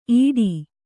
♪ īḍi